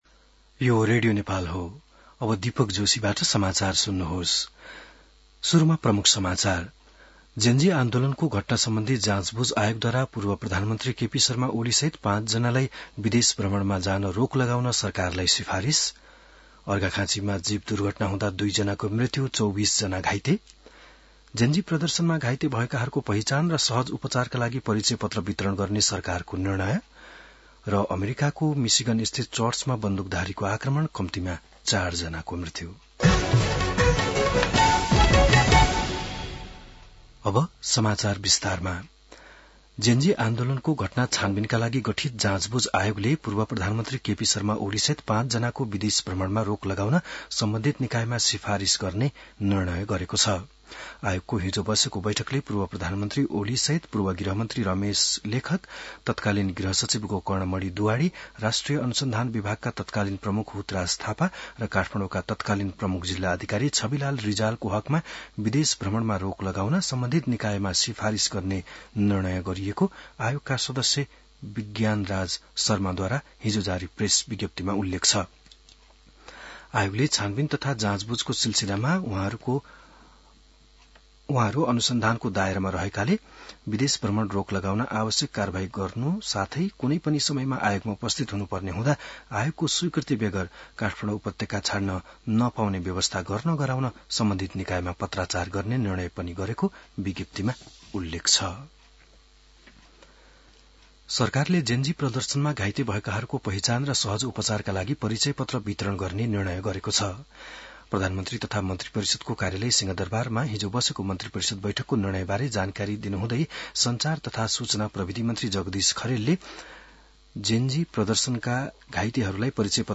बिहान ९ बजेको नेपाली समाचार : १३ असोज , २०८२